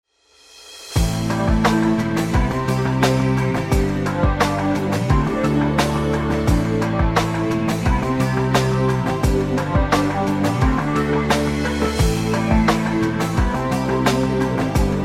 Instrumental, Pop